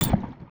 UIClick_Menu Water Splash Metal Hit 04.wav